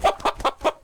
CosmicRageSounds / ogg / general / combat / creatures / chicken / he / attack1.ogg